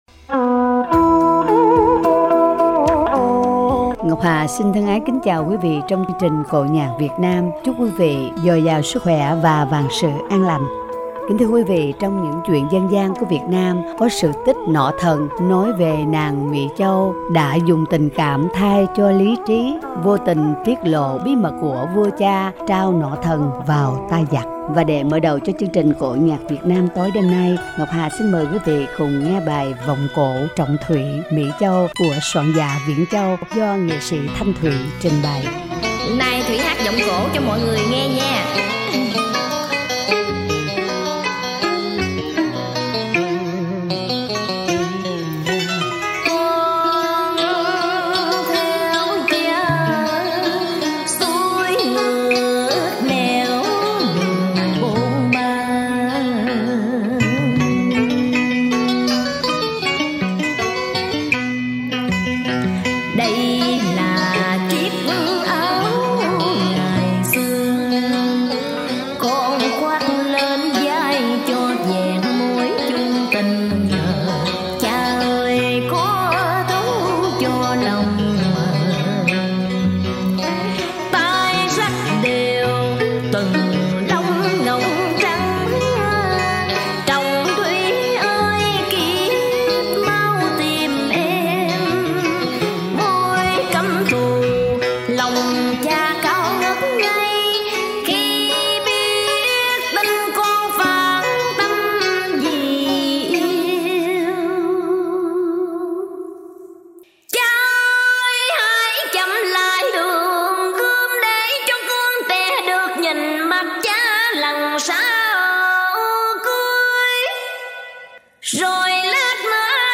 Vọng cổ
tân cổ